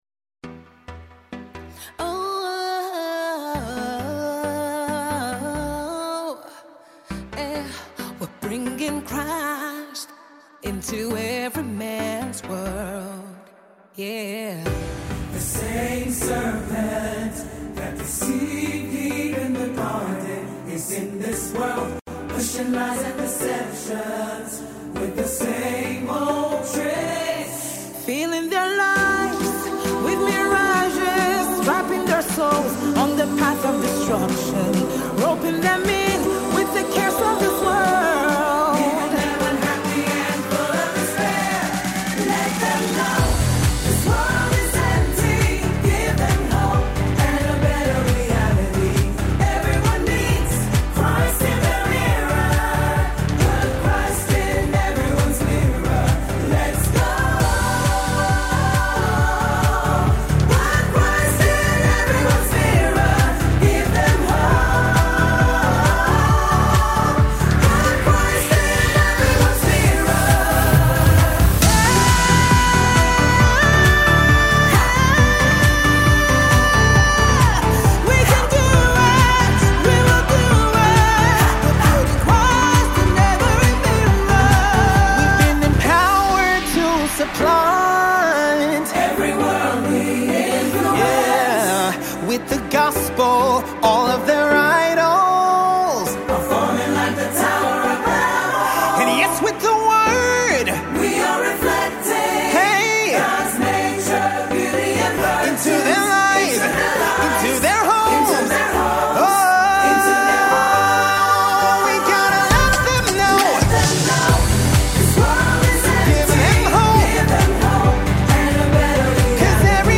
Carol